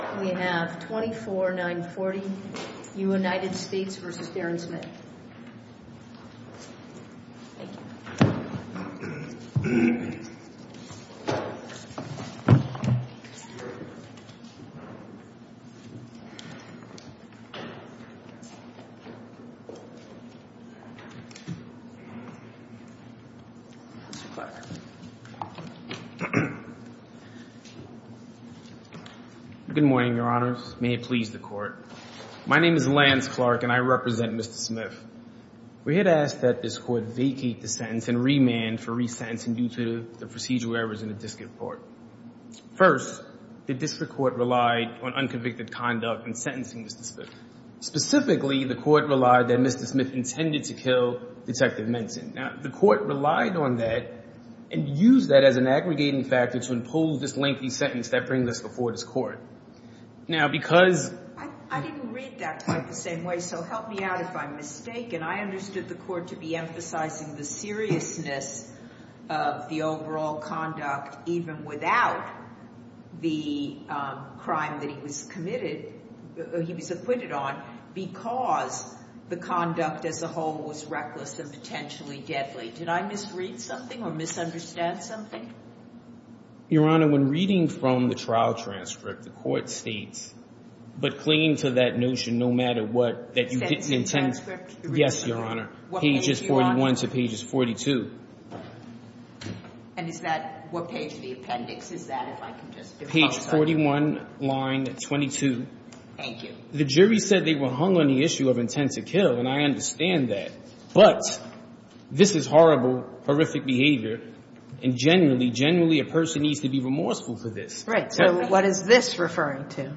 Oral Arguments for the Court of Appeals for the Second Circuit
A chronological podcast of oral arguments with improved files and meta data.